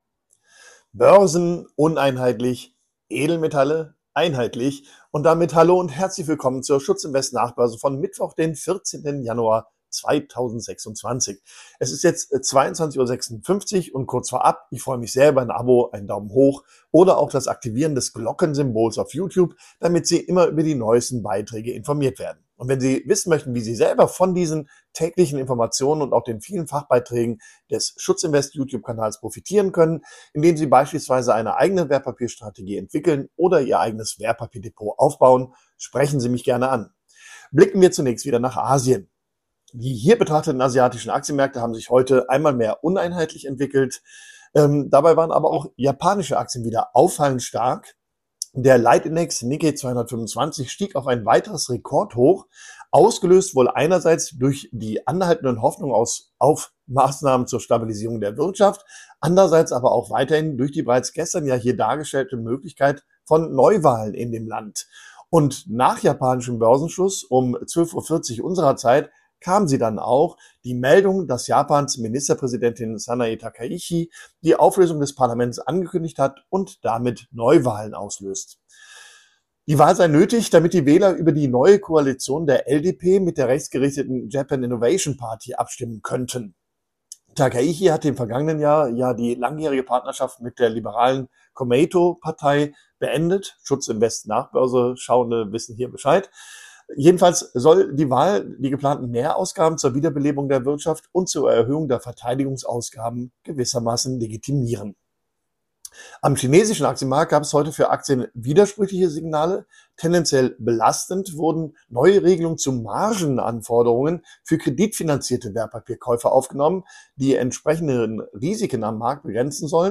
Beschreibung vor 2 Monaten Die schutzinvest-Nachbörse und das Börsenquiz: Der börsentägliche Marktkommentar zum Börsengeschehen nach US-Börsenschluss, also in der Regel zwischen 22:00 und 23:00 Uhr. Dazu ein kleines Börsenquiz rund um das Thema Börse und Finanzen. Die wichtigsten Ereignisse des Börsentages prägnant zusammengefasst und "Wissen to go".